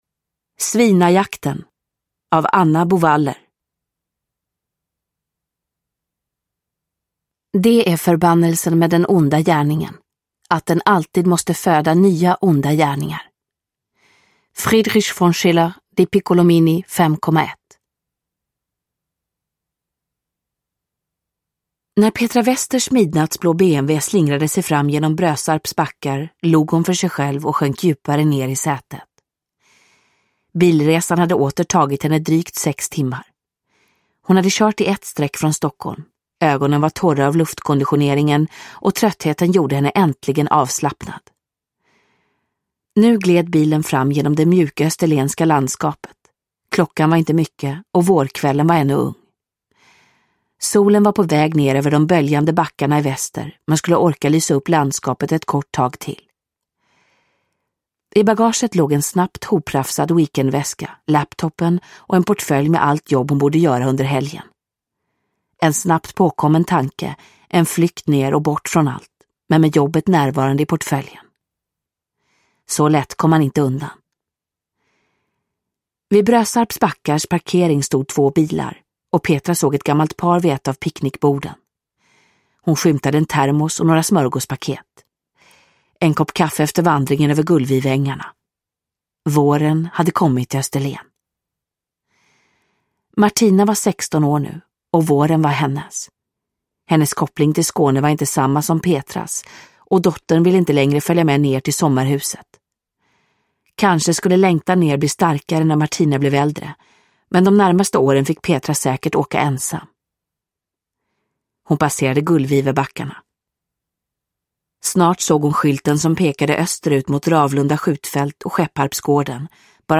Nedladdningsbar Ljudbok